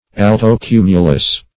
Search Result for " alto-cumulus" : The Collaborative International Dictionary of English v.0.48: Alto-cumulus \Al`to-cu"mu*lus\, n. [L. altus high + L. & E. cumulus.]